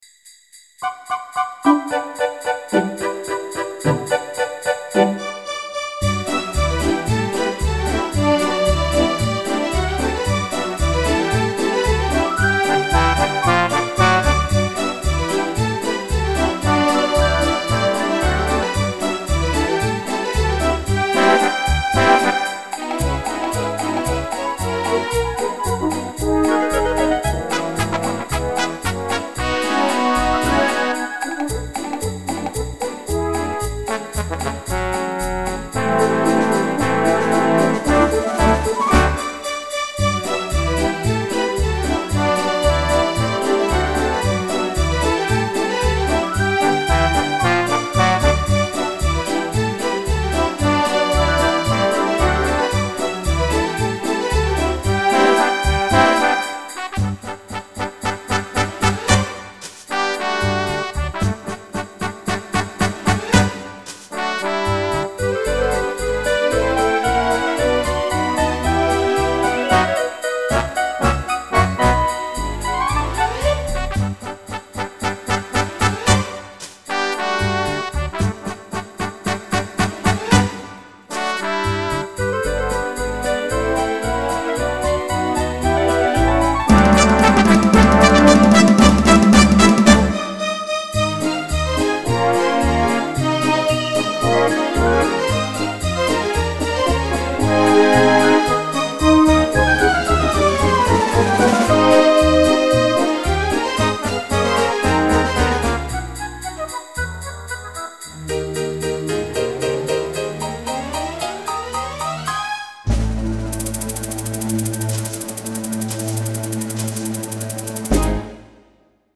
Nobody’s gonna sing this arrangement, I know.